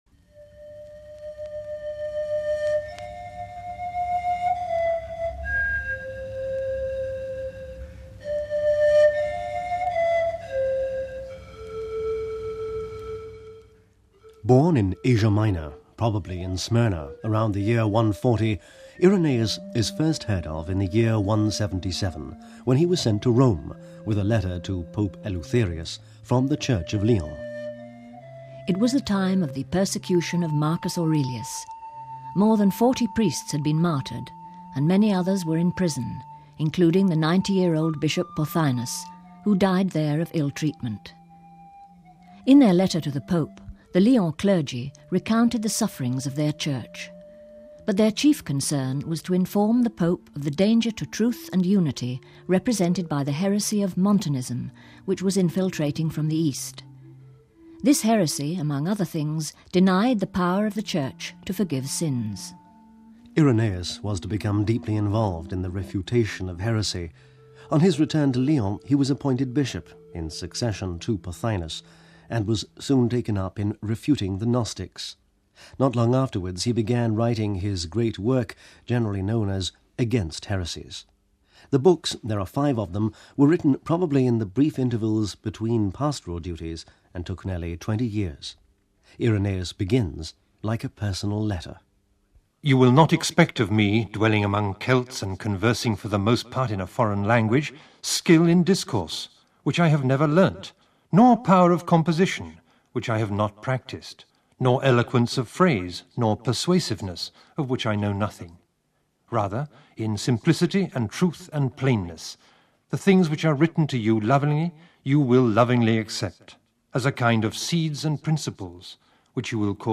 We bring you a sound portrait of the great Father of the Church , Saint Irenaeus whose name means peacemaker. Bishop of Lyons , known as the Apostle of the Gauls he was the Church's first theologian .